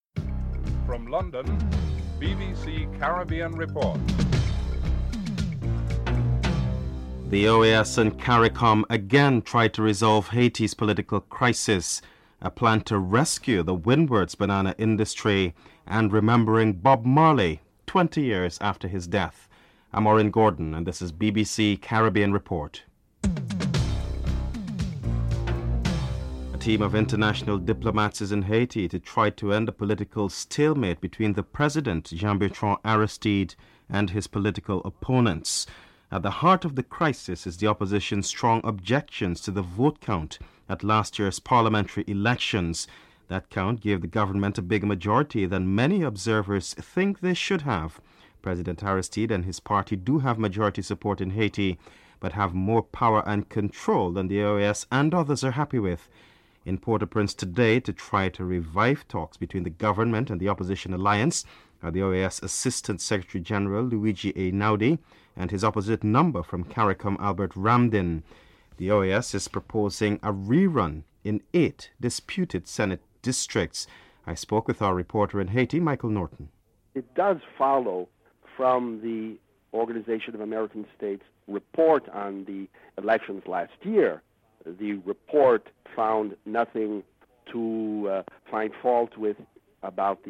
1. Headlines (00:00-00:25)
Bob Marley and several Rastafarians are interviewed.